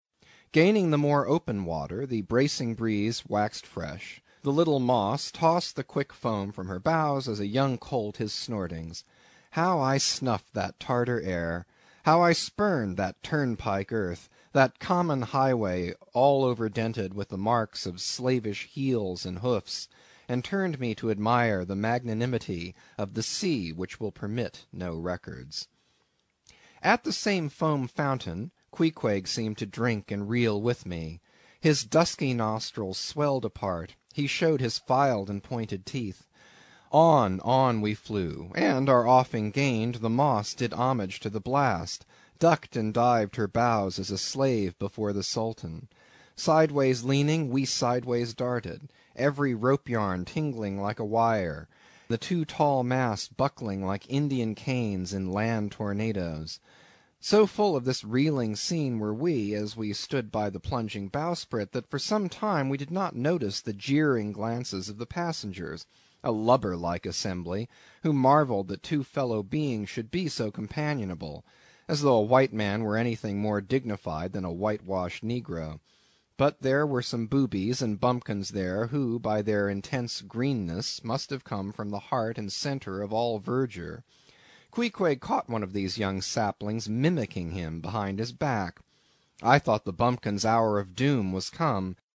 英语听书《白鲸记》第266期 听力文件下载—在线英语听力室